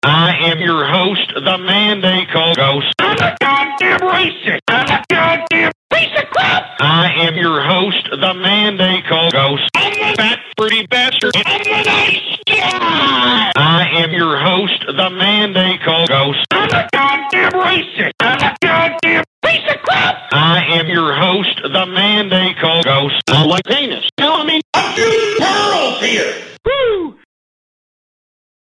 Chorus Vox All .mp3